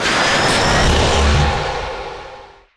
engine_flyby_004.wav